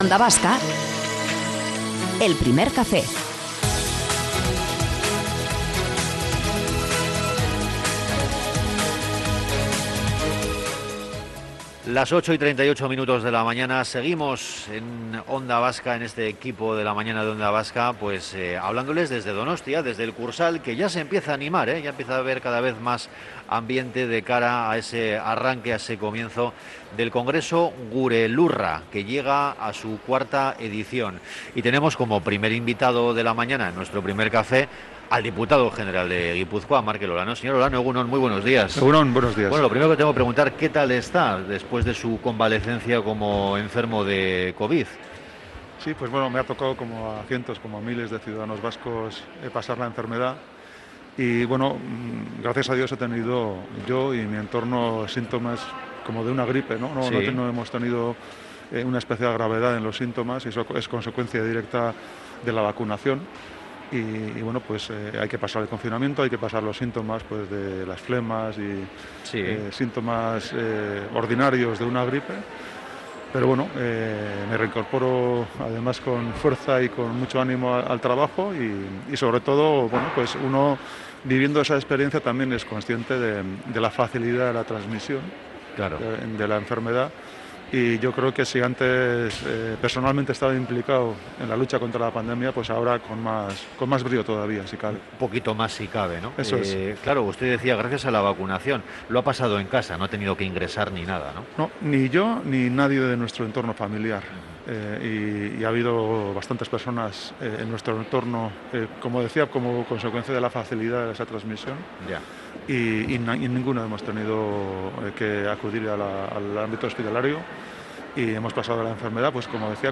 Entrevista completa con Markel Olano - Onda Vasca
Morning show conectado a la calle y omnipresente en la red.